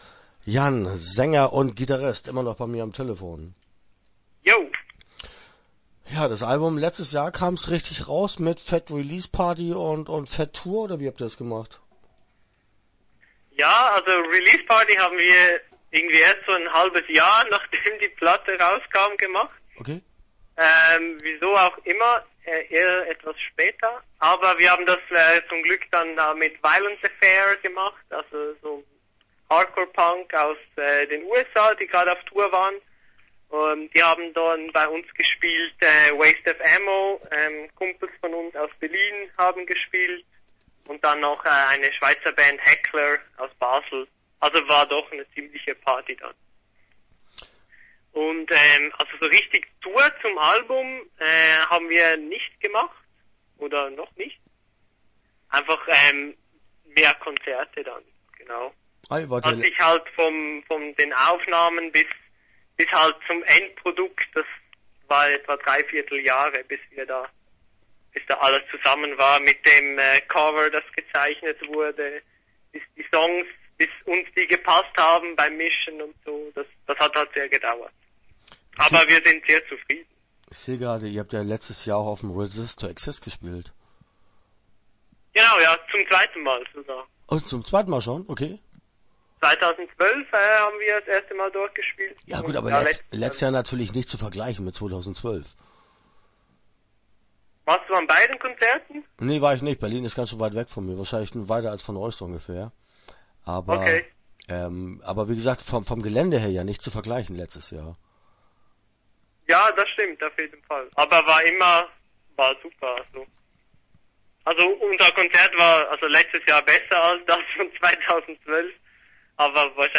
Defused - Interview Teil 1 (10:29)